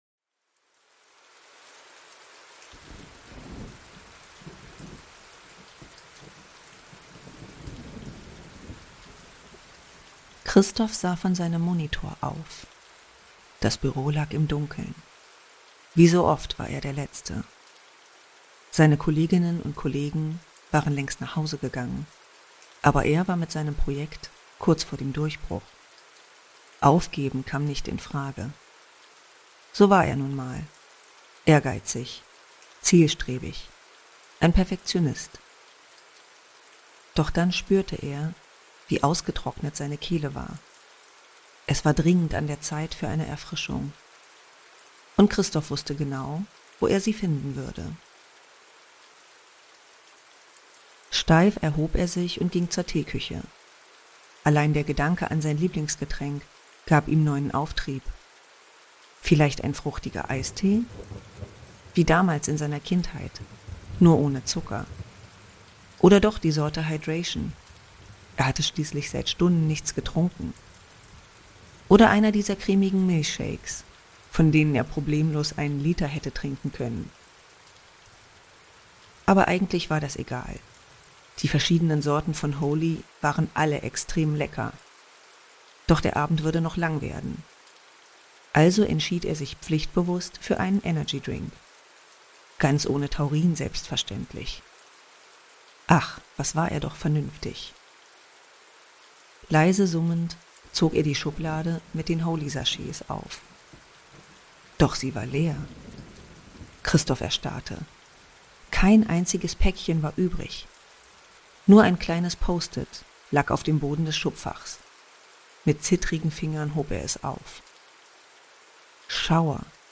SCHAUERSTOFF Language: de Genres: True Crime Contact email: Get it Feed URL: Get it iTunes ID: Get it Get all podcast data Listen Now... Best of Schauerstoff: Branntkalk (Reupload)